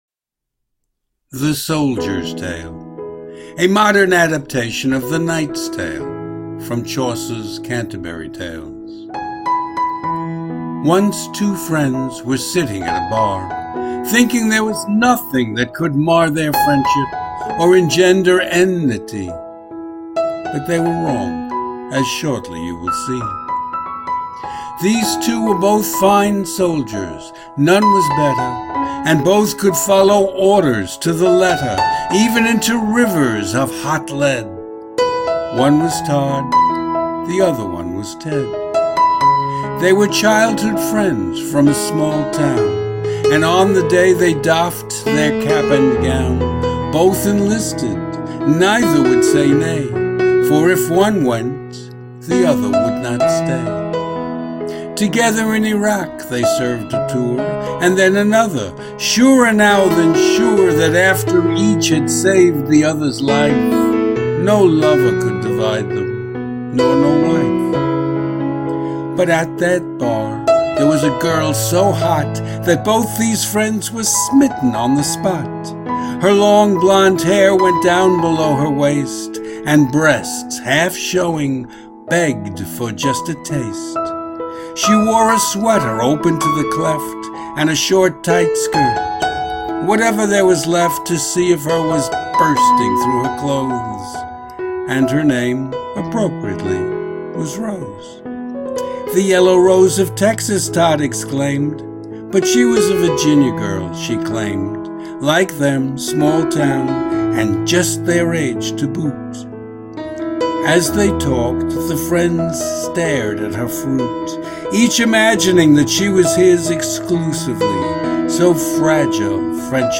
Hear me read The Soldier's Tale as an MP3 file.